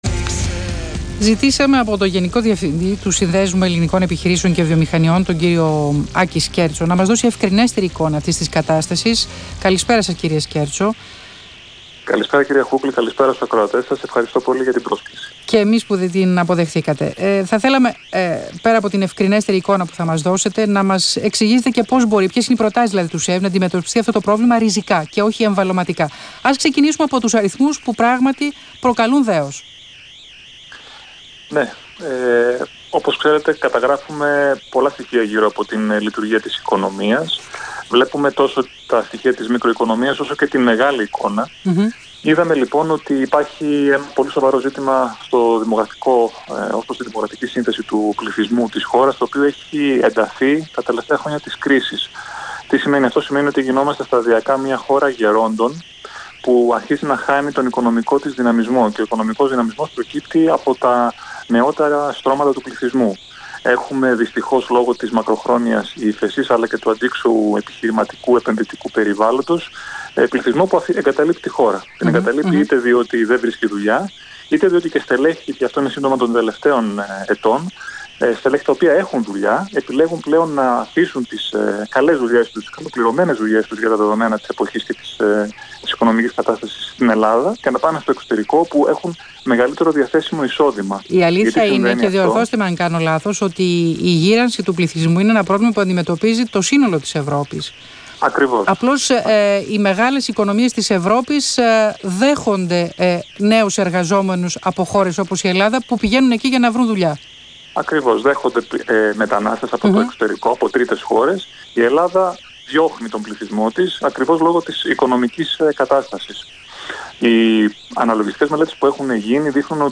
Συνέντευξη του Γενικού Διευθυντή του ΣΕΒ, κ. Άκη Σκέρτσου στον Ρ/Σ Αθήνα 9.84, 6/10/2017